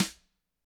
Index of /90_sSampleCDs/ILIO - Double Platinum Drums 1/CD4/Partition C/GRETCHBRSNRD